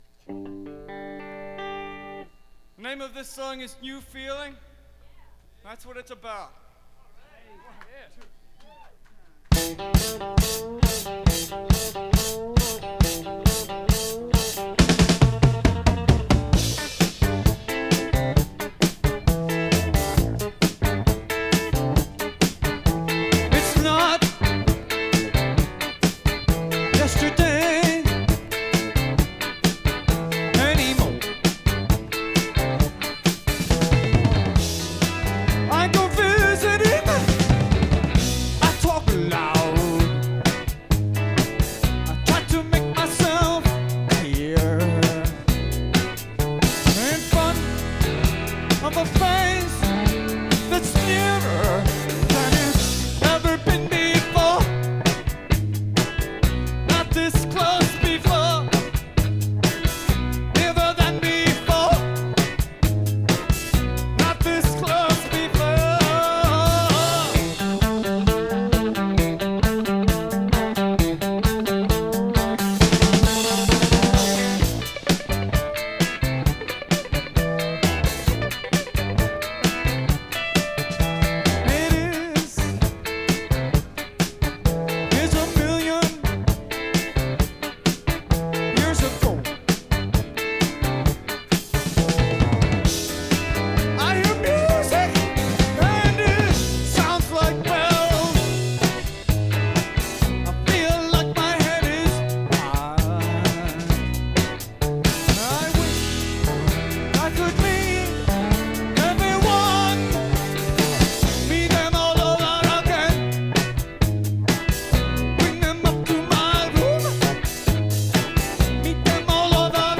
Recorded November 17th, 1977 in Massachusetts
Source: vinyl rip